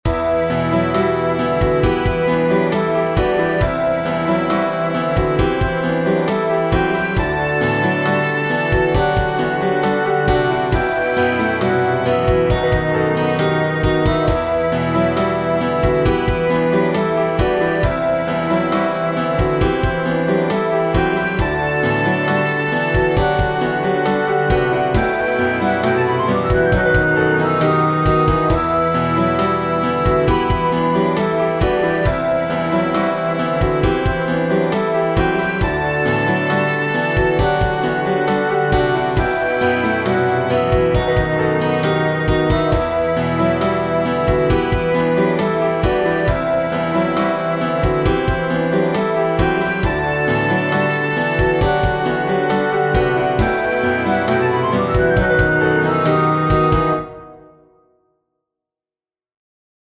Feels like Avengers so yeah!